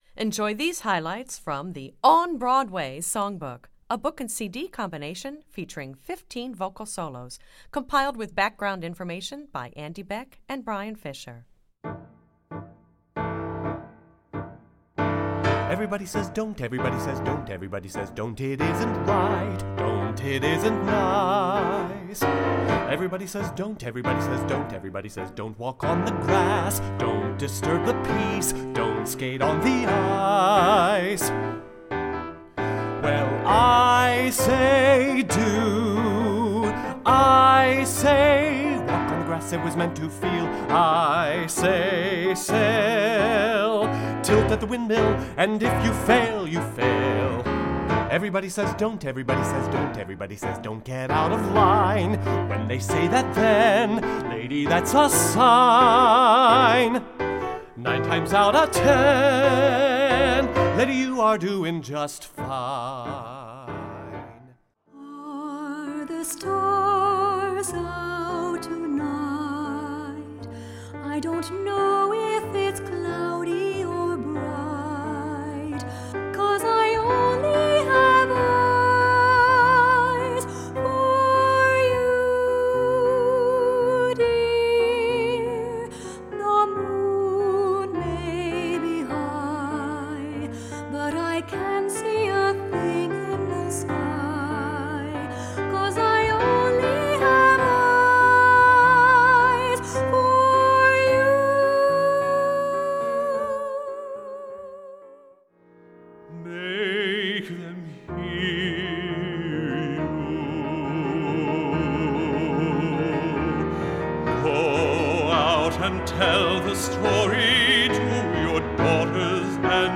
Vocal Solo Level